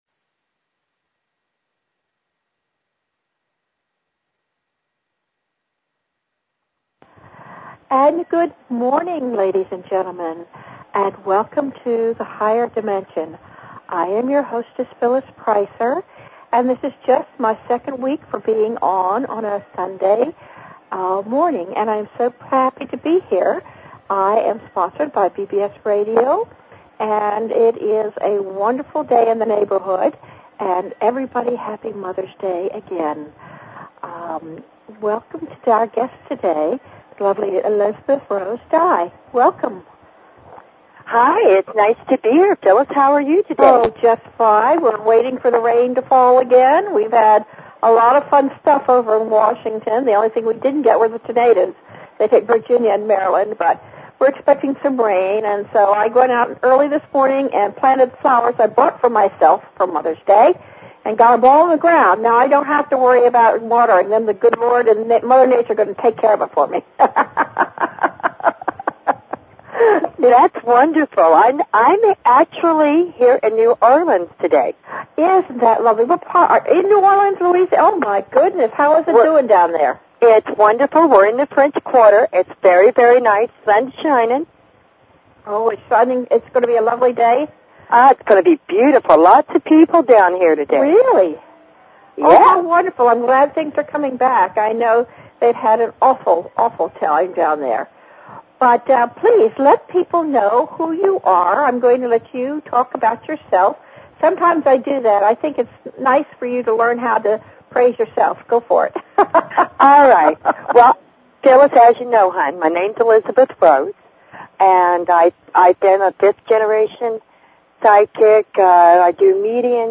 Talk Show Episode, Audio Podcast, Higher_Dimensions and Courtesy of BBS Radio on , show guests , about , categorized as